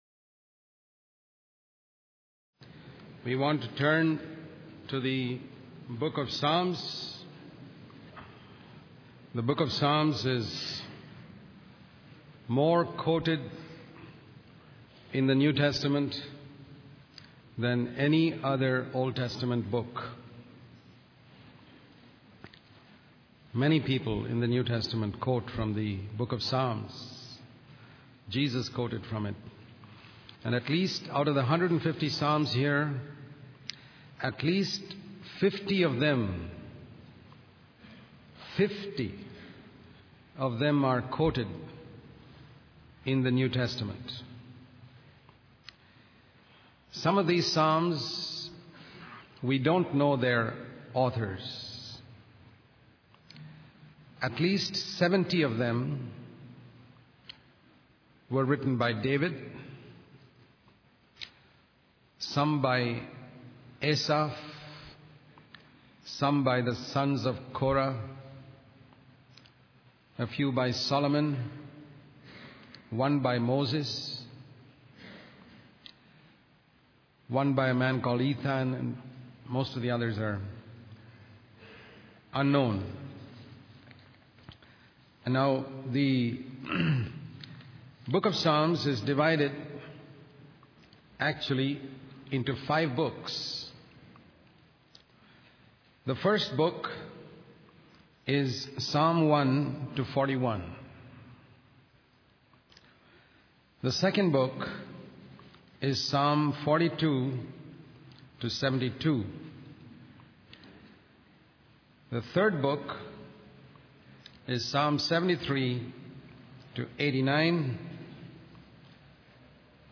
In this sermon, the speaker emphasizes the importance of filling one's heart with the Word of God rather than simply avoiding evil.